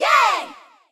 yayGirls.ogg